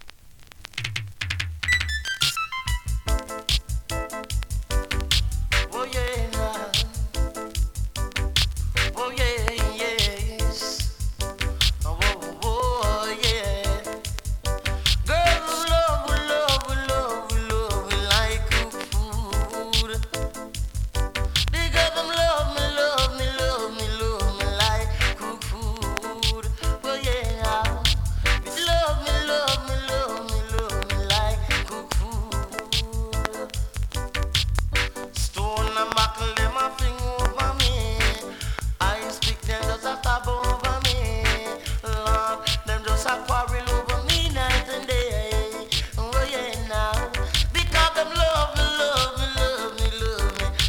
ホーム > 2024 NEW IN!! DANCEHALL!!
スリキズ、ノイズそこそこありますが